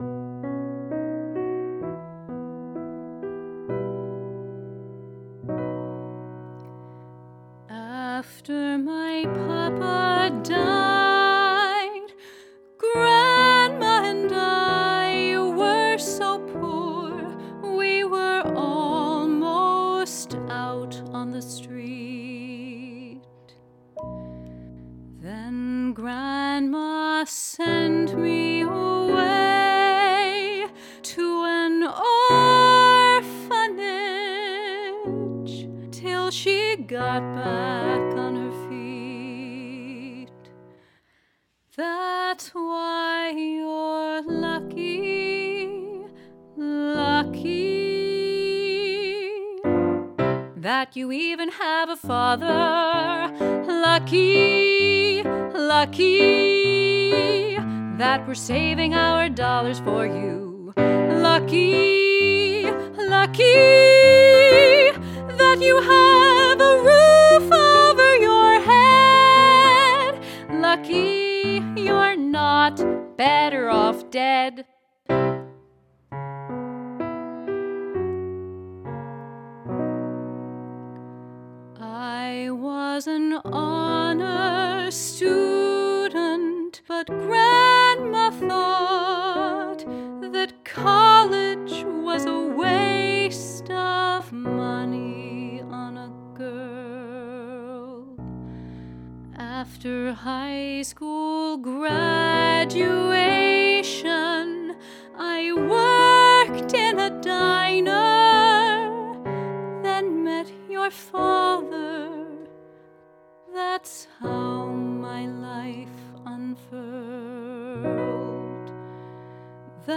A dramatic and comic song.
Piano